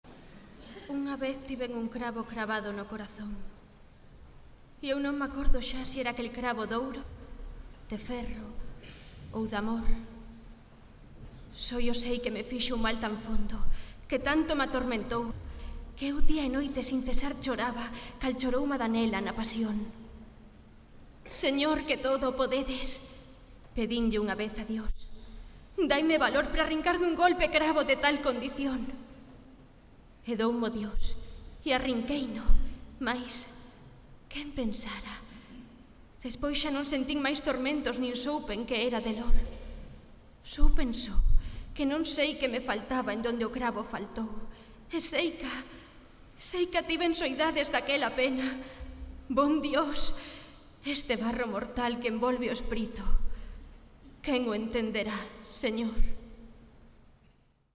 LOCUCIÓN Y DOBLAJE
poema-de-rosalia-de-castro.mp3